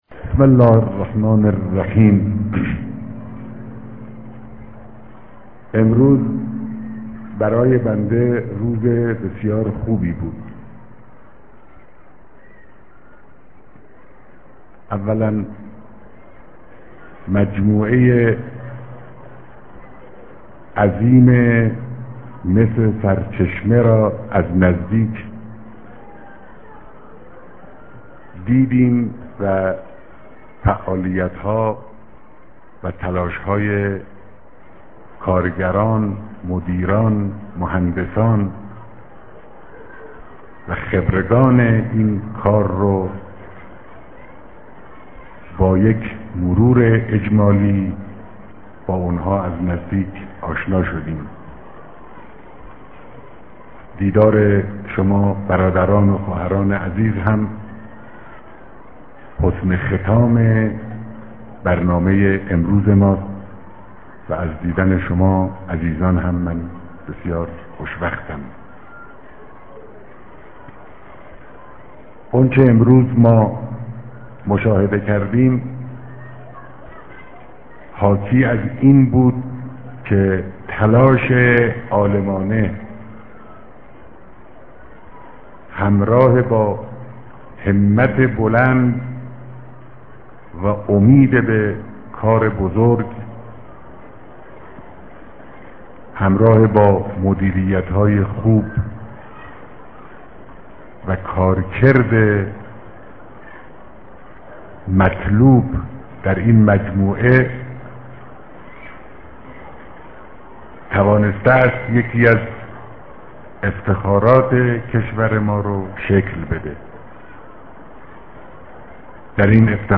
در جمع هزاران نفر از کارکنان مجتمع مس سرچشمه